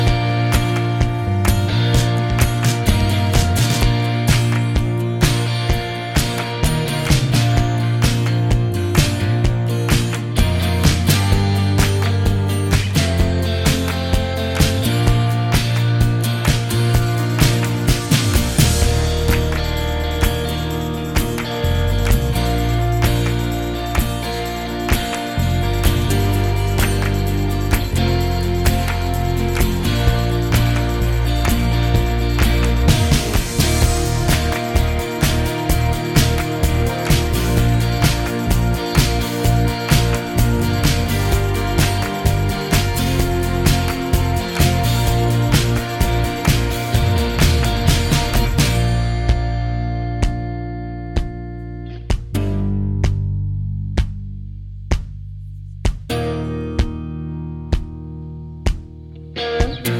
Acoustic in Female Key Pop (2020s) 3:43 Buy £1.50